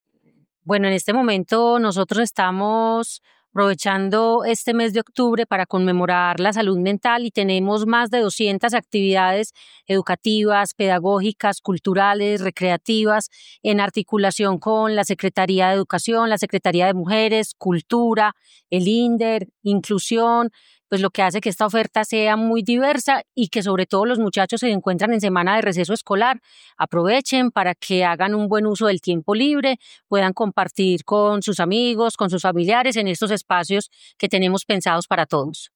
Declaraciones secretaria de Salud, Natalia López
Declaraciones-secretaria-de-Salud-Natalia-Lopez.mp3